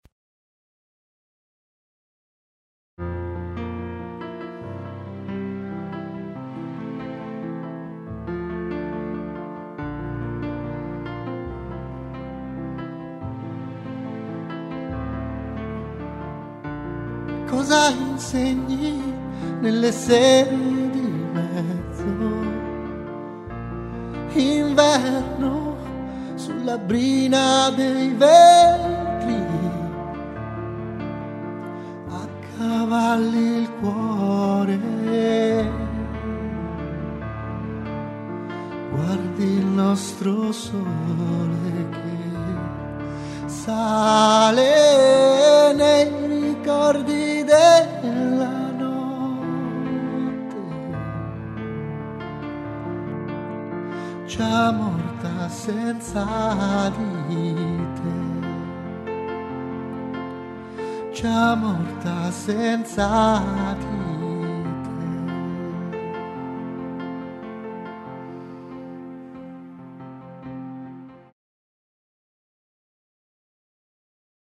musicato e cantato